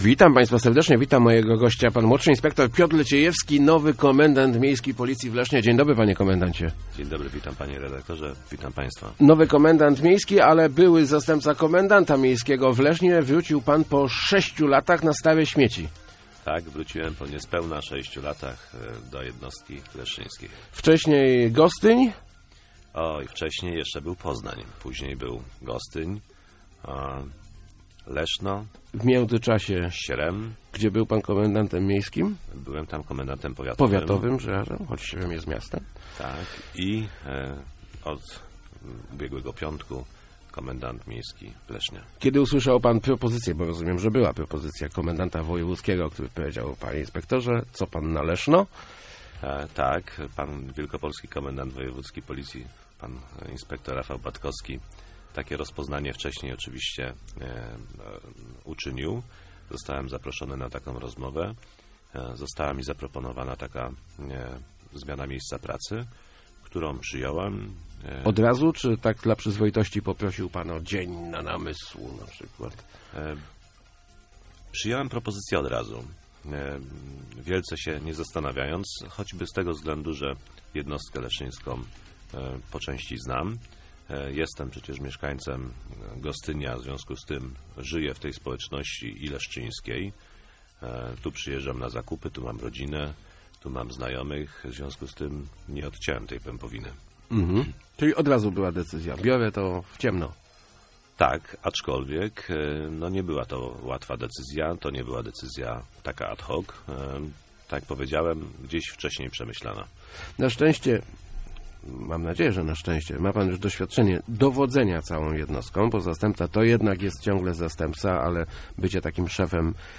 Nie odpuścimy żadnej sprawy - mówił w Rozmowach Elki Piotr Leciejewski, nowy Komendant Miejski Policji w Lesznie. Zapowiada on zwłaszcza więcej patroli pieszych w Lesznie, dzięki czemu policjanci będą bliżej mieszkańców i ich problemów.